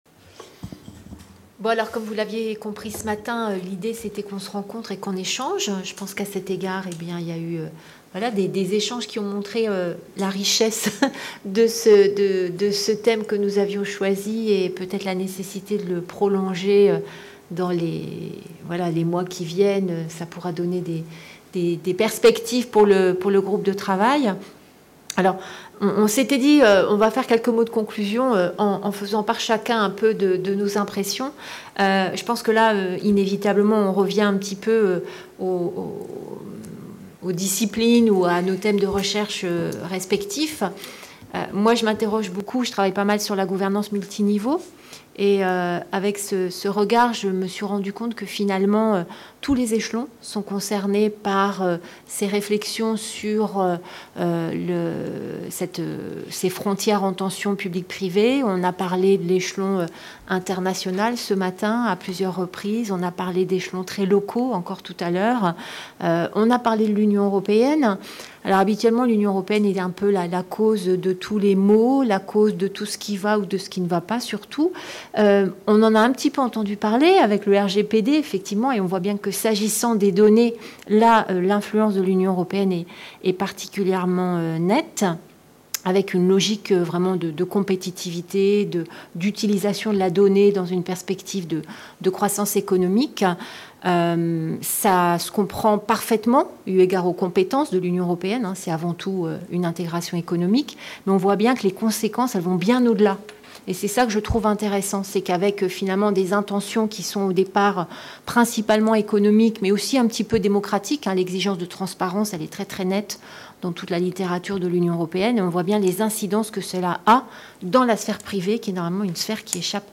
Conclusion de la journée d’étude du pôle Gouvernance de la MSHB, Public / Privé : des frontières sous tension.